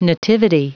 Prononciation du mot nativity en anglais (fichier audio)
Prononciation du mot : nativity